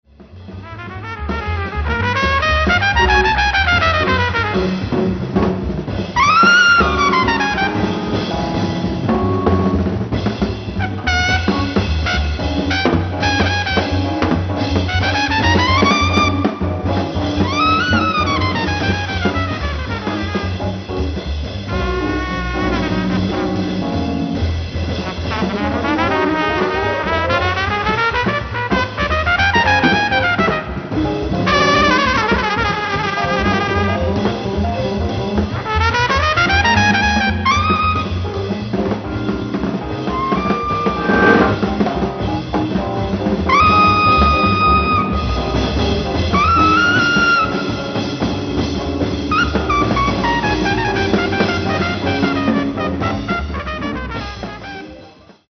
Live At Salley Pleyel, Paris, France 11/03/1969
SOUNDBOARD RECORDING